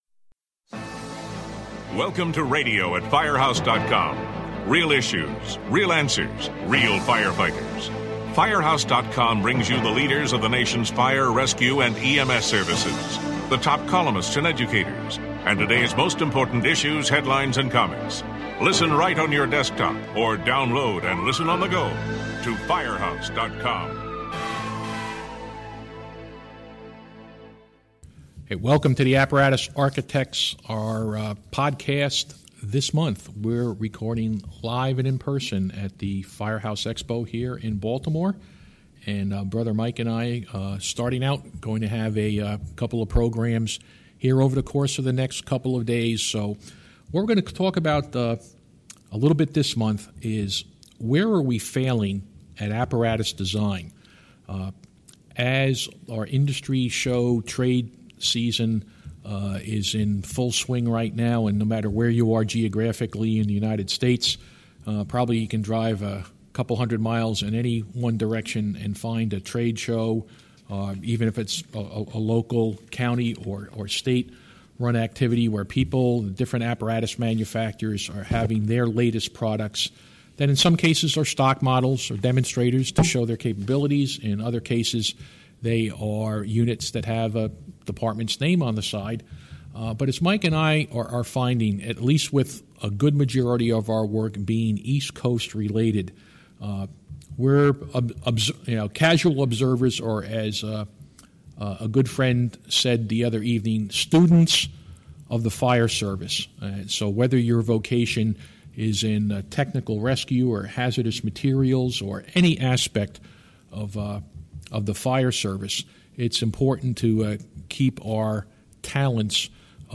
The Apparatus Architects have been spending a lot of time on the road visiting new apparatus deliveries and met at Firehouse Expo to discuss some of the red flags that they are seeing.